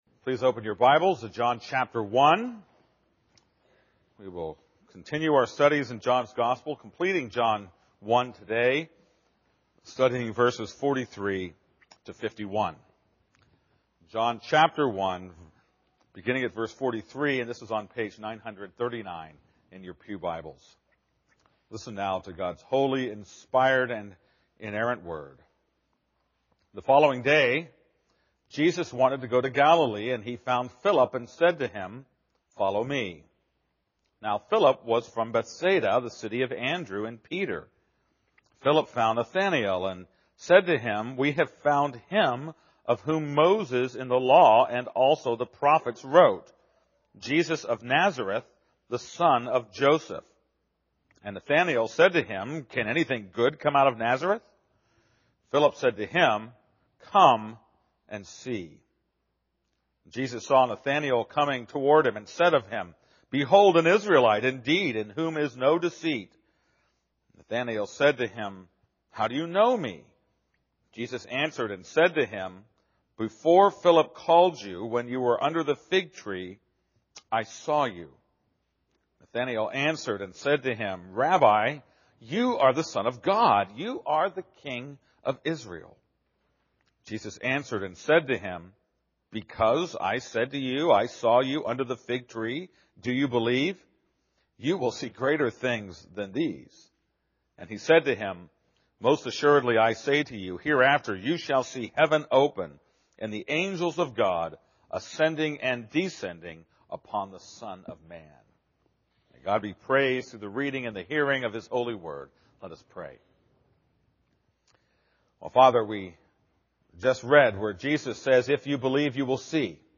This is a sermon on John 1:43-51.